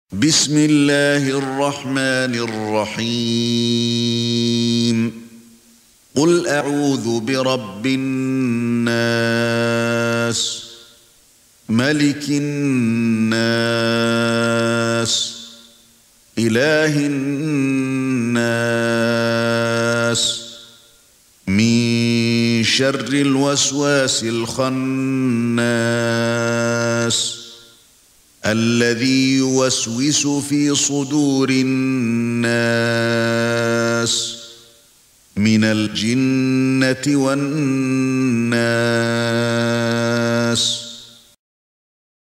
سورة الناس ( برواية قالون ) > مصحف الشيخ علي الحذيفي ( رواية قالون ) > المصحف - تلاوات الحرمين